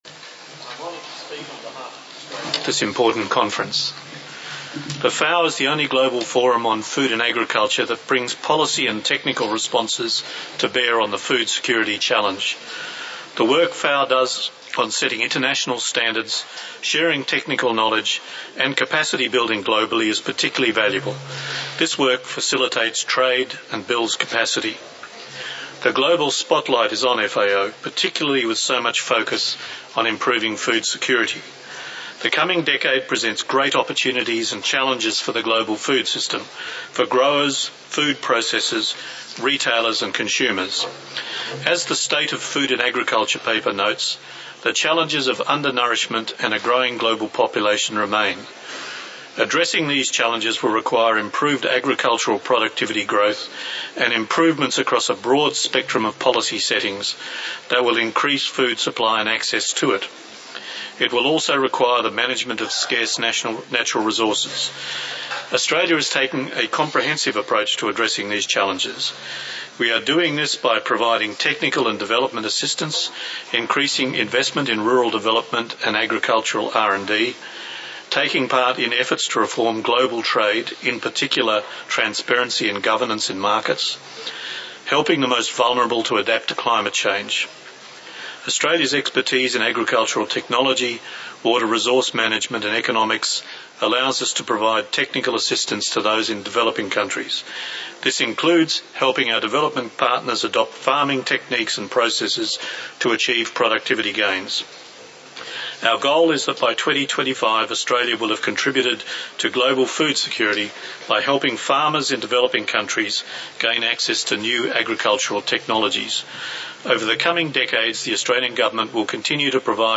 Statements by Heads of Delegations under Item 9:
His Excellency David J. Ritchie Ambassador, Permanent Representative of Australia to FAO